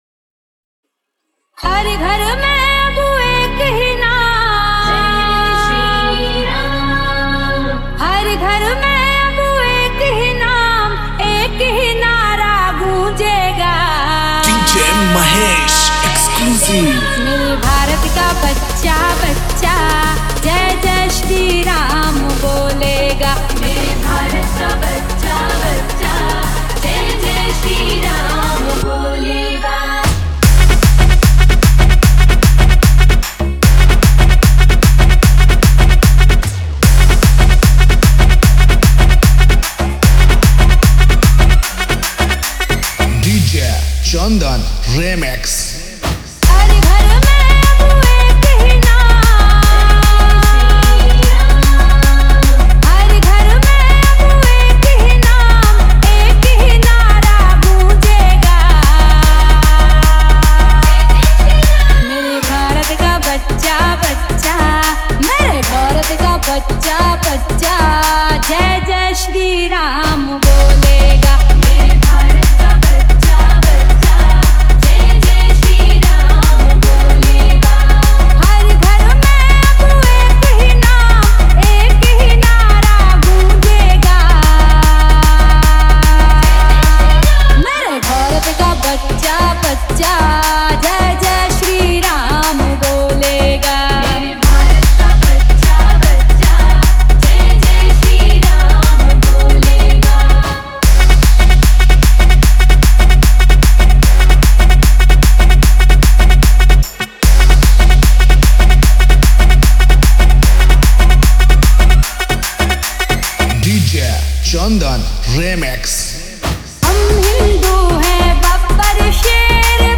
Ram Navami Special Dj Songs Download